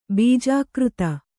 ♪ bījākřta